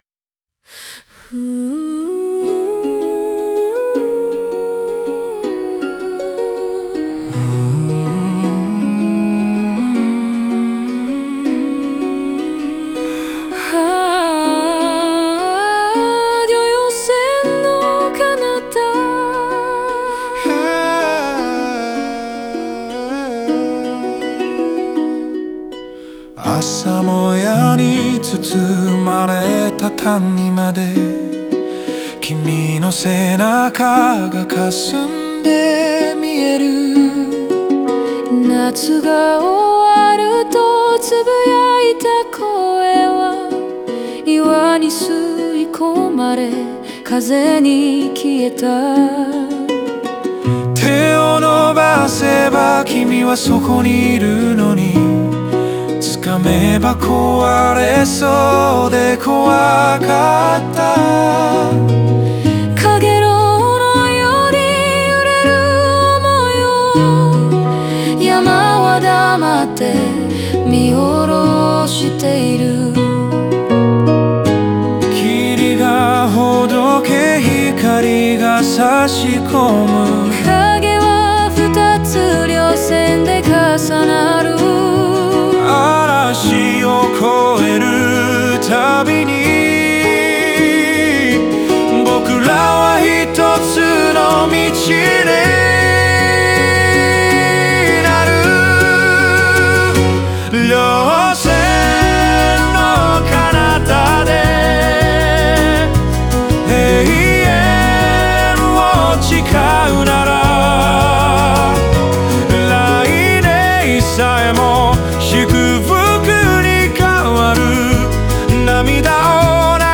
男女の掛け合いとユニゾンで構成されたサビでは、衝突や不安を乗り越え、互いの存在を確かめ合う瞬間が描かれます。